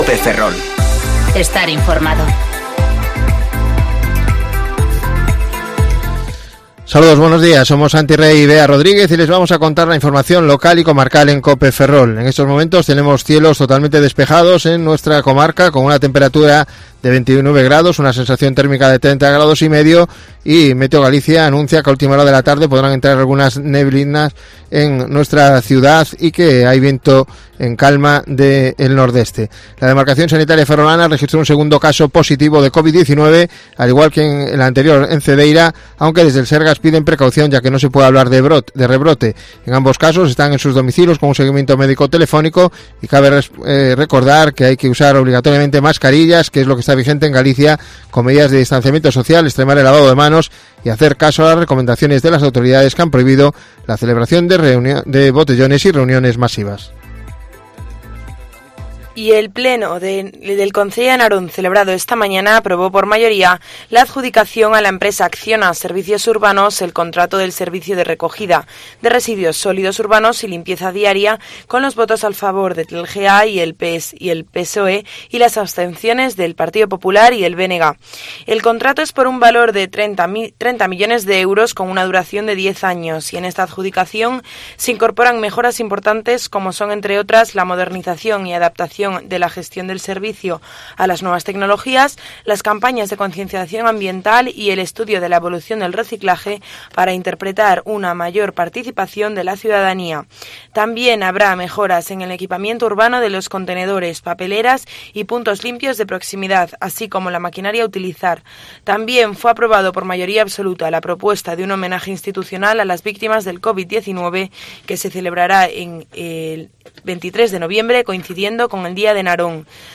Informativo Cope Ferrol 30/07/2020 (De 14:20 a 14:30 horas)